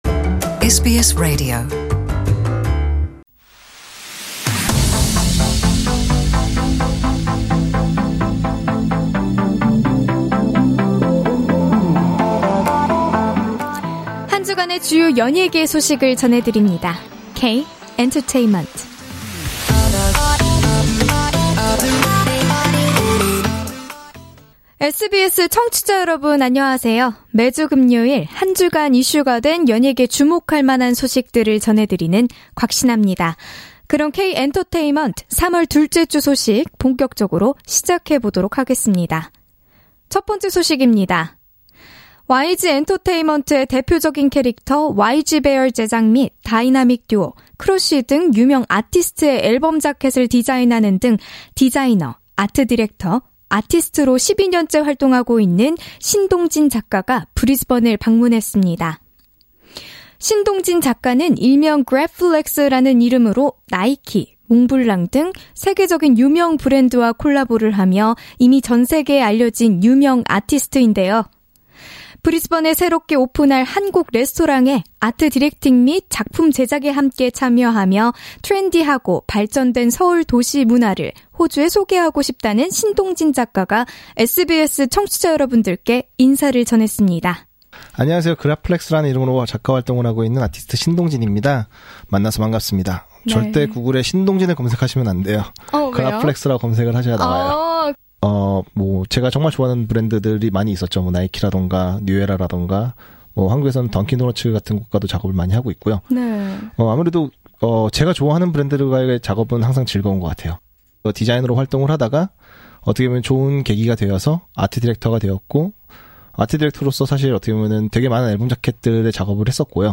This week's K-Entertainment features an exclusive interview with him and also other compelling news such as JK Entertainment's announcement that K-pop Ballard Queen Baek Ji Young will fly to Sydney, Australia for the concert on 18th, May. Moreover, the earth-conquering South Korean pop girl group BLACKPINK have announced their first ever tour of Australia.